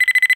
enemy_Spider_AlertSound.wav